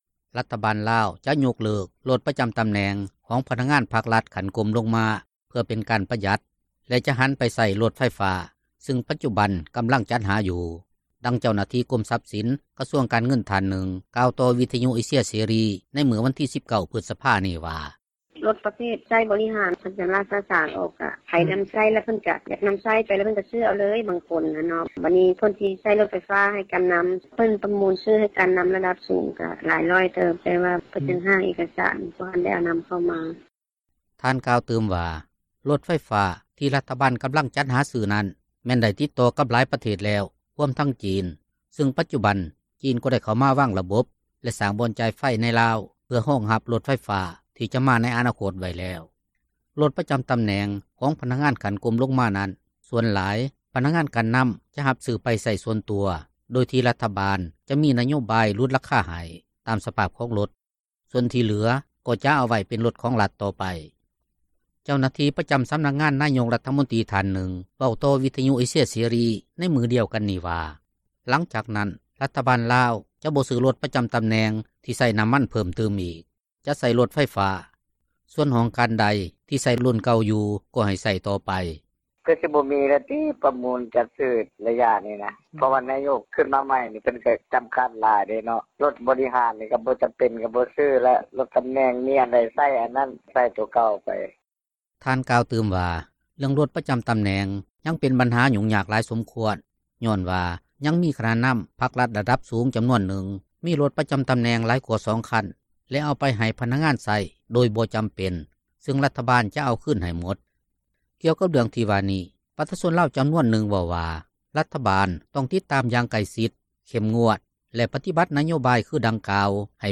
ດັ່ງເຈົ້າໜ້າທີ່ກົມຊັພສິນ ກະຊວງການເງິນທ່ານນຶ່ງ ກ່າວຕໍ່ວິທຍຸເອເຊັຽເສຣີໃນມື້ວັນທີ 19 ພຶສພານີ້ວ່າ:
ດັ່ງປະຊາຊົນລາວຜູ້ນຶ່ງເວົ້າຕໍ່ ວິທຍຸເອເຊັຽເສຣີໃນມື້ວັນທີ 19 ພຶສພານີ້ວ່າ: